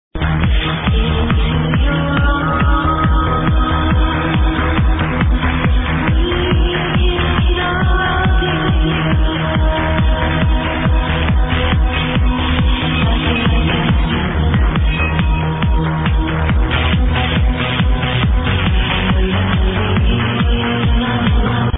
Sorry for the bad quality of the sample !